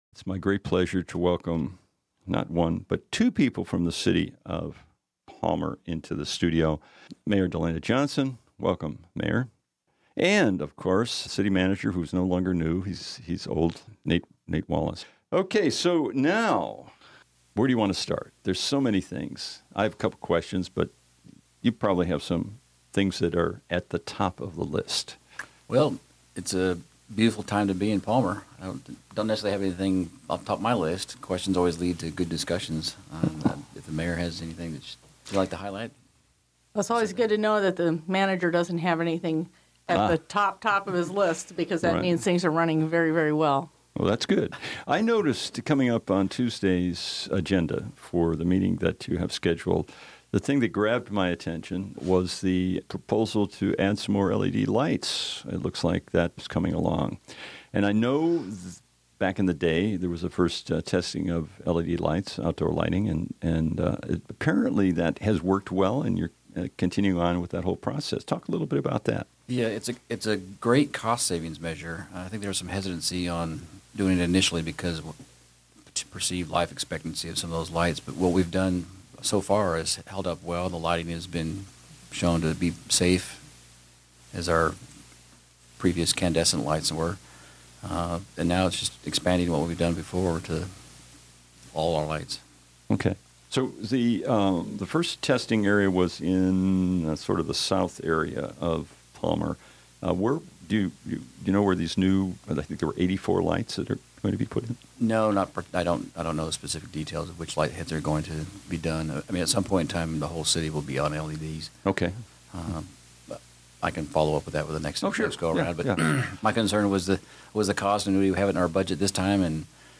interviewed the Mayor and Manager about the state of the City.